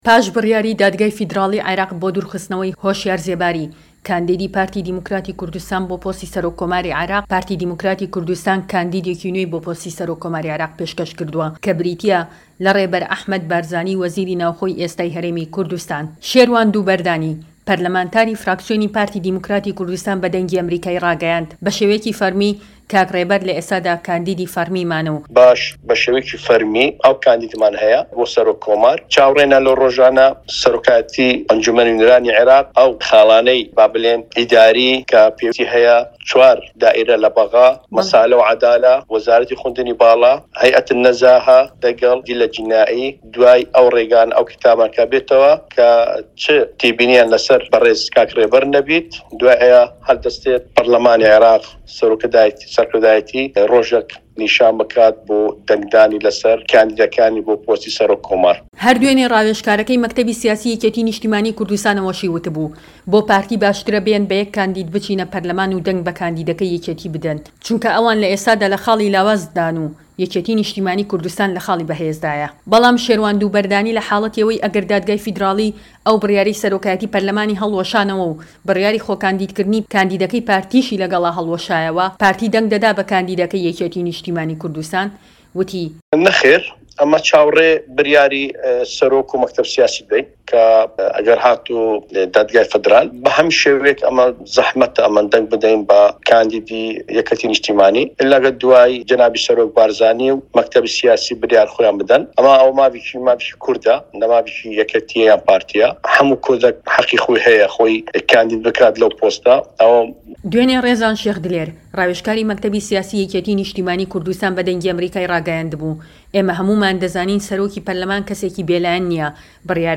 وتووێژ لەگەڵ شێروان دووبەردانی